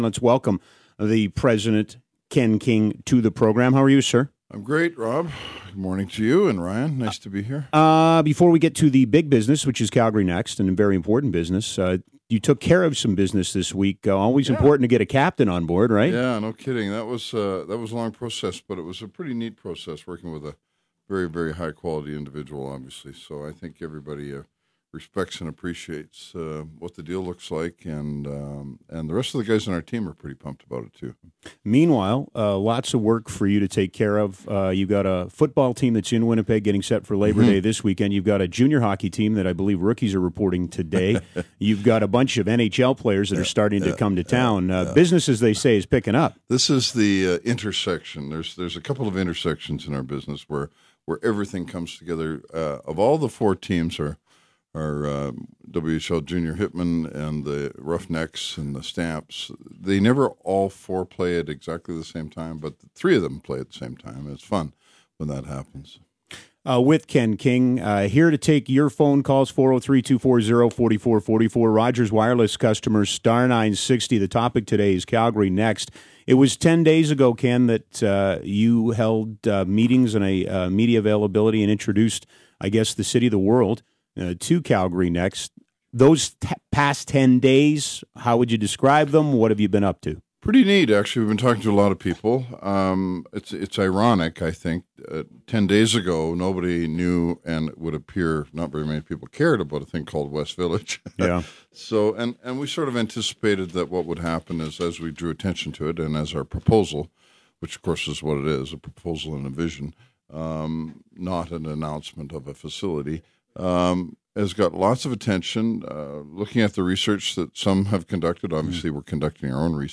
It was a "townhall" where 960 listeners would call in to ask questions. There wasn't anything new discussed really.